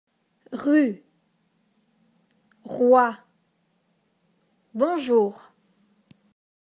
Le son [R]
Pour prononcer le son [R] en français on place le bout de la langue sur les dents inférieures et on fait vibrer les cordes vocales.